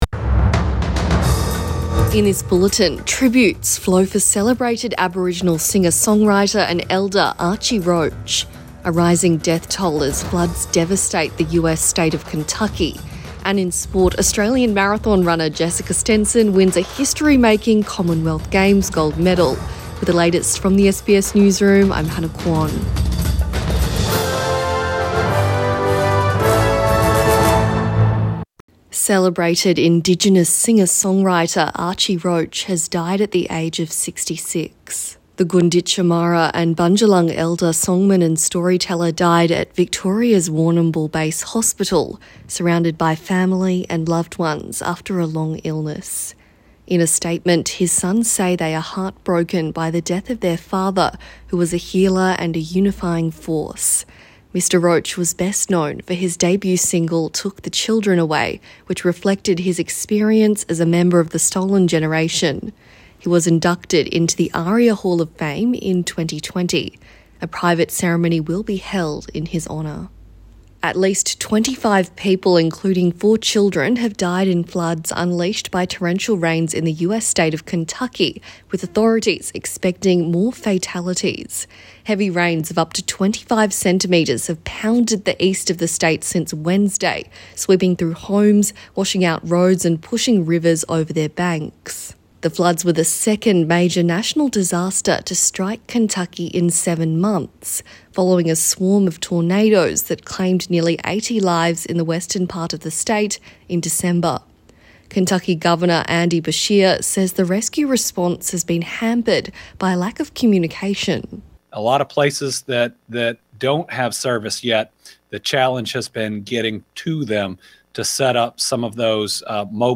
AM bulletin 31 July 2022